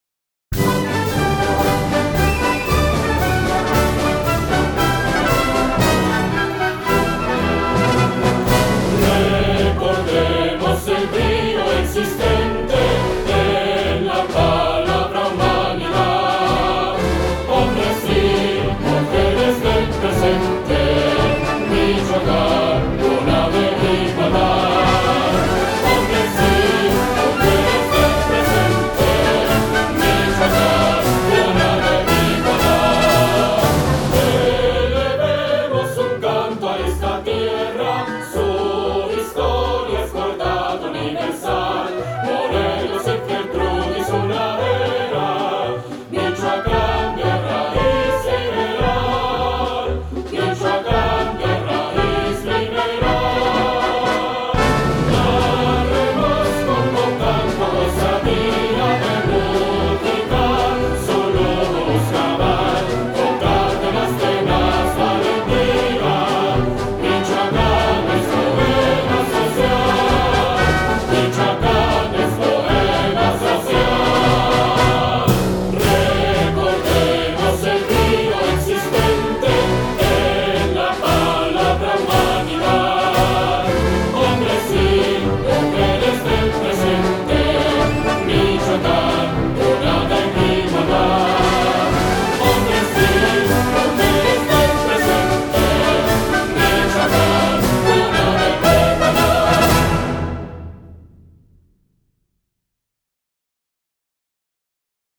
Himno versión corta (1:45)